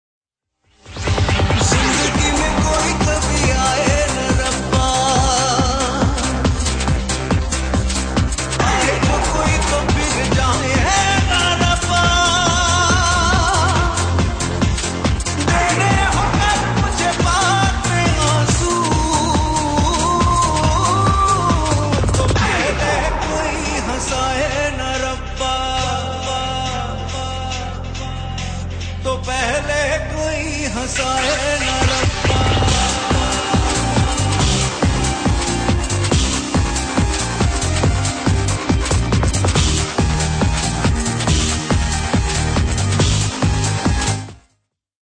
Bollywood & Indian